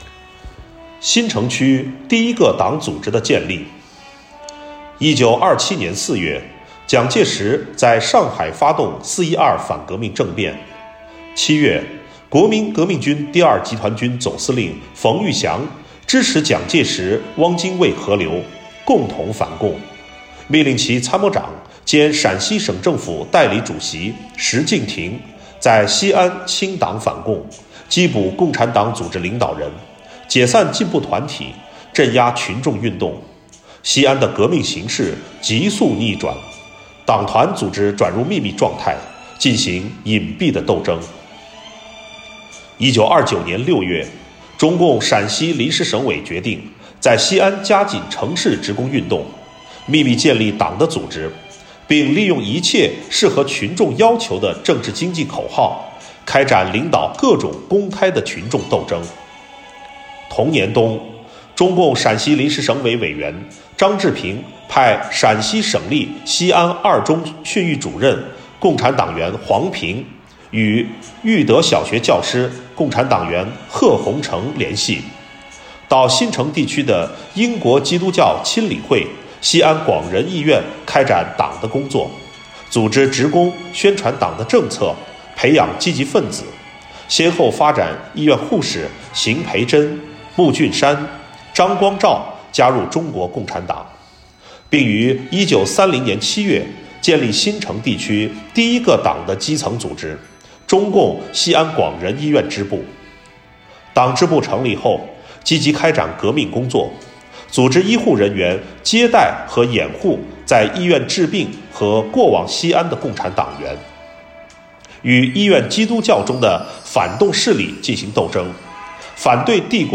【红色档案诵读展播】新城区党组织的建立